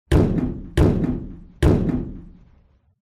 На этой странице собрана коллекция звуков и голосовых фраз Haggy Wagy.
Стук